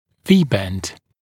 [‘viːbend][‘ви:бэнд]V-образный изгиб